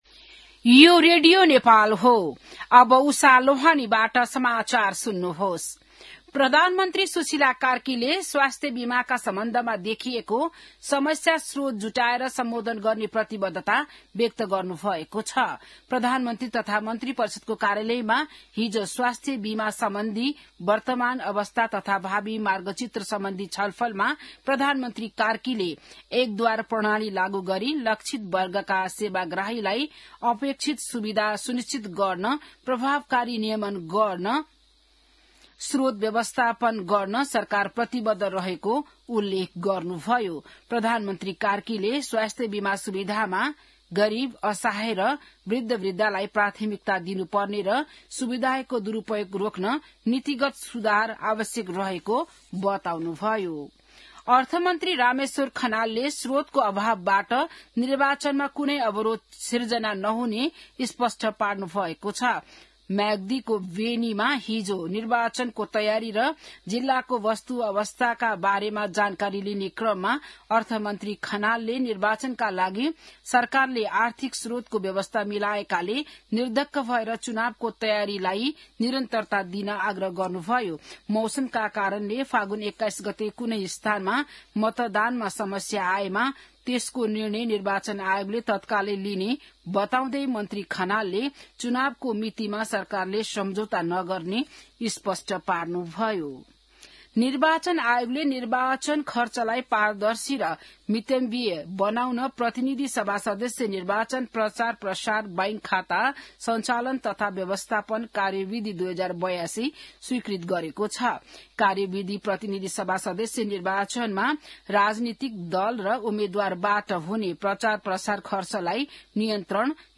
बिहान १० बजेको नेपाली समाचार : २२ माघ , २०८२